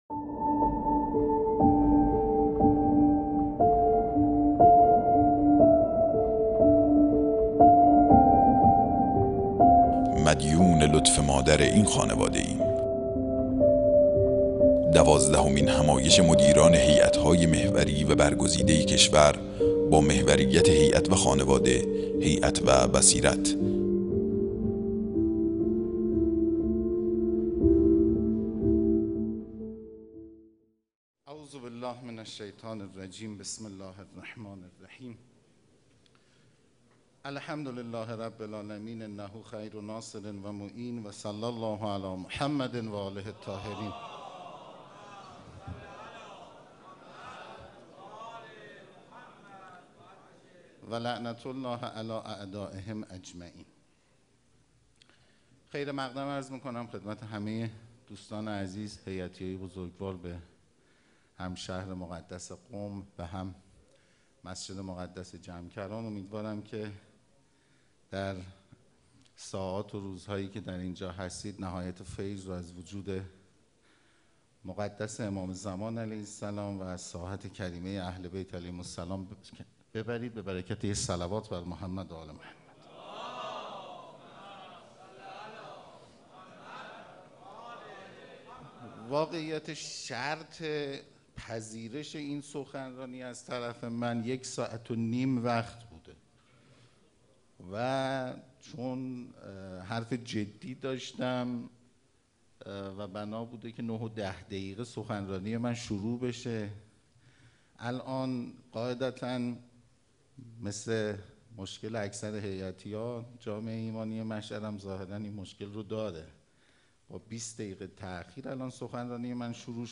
سخنرانی
دوازدهمین همایش هیأت‌های محوری و برگزیده کشور با محوریت هیأت و خانواده، هیأت و بصیرت | شهر مقدس قم - مسجد مقدس جمکران